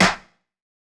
Snare Rnb 1.wav